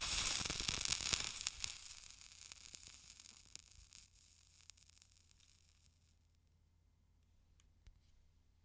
steam hiss